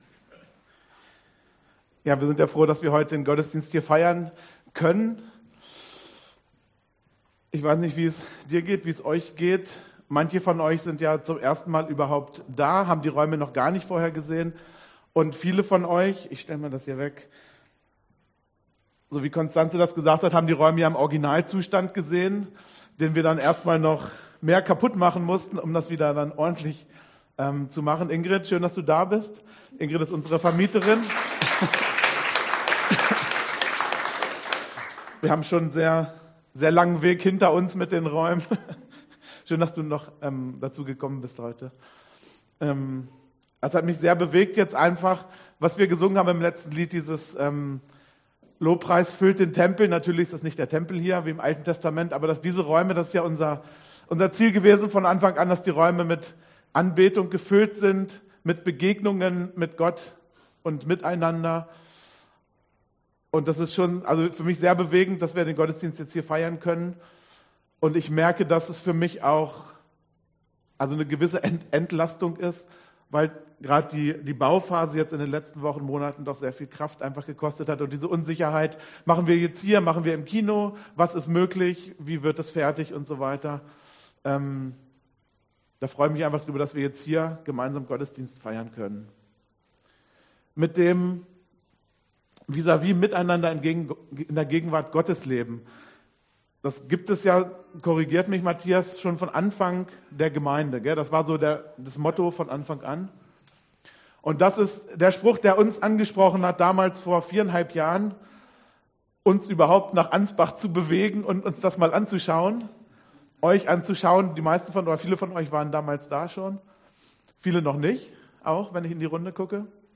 Die erste Predigt in unseren neuen Räumen!